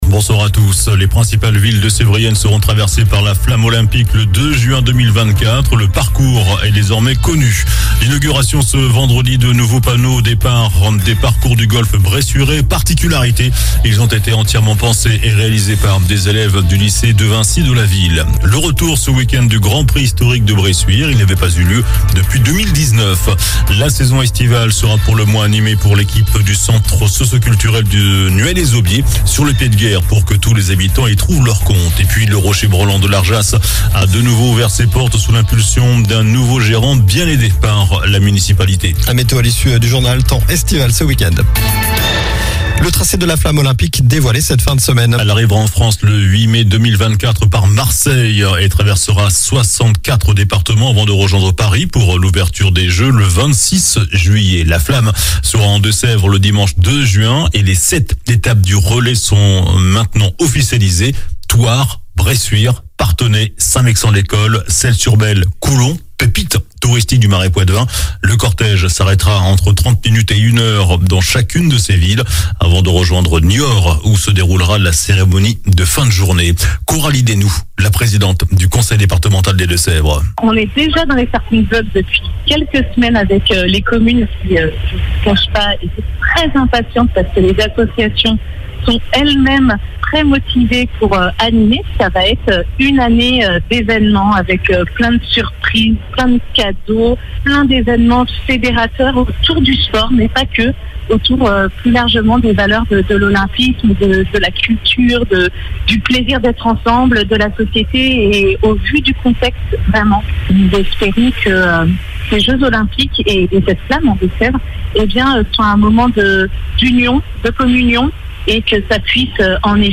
Journal du vendredi 23 juin (soir)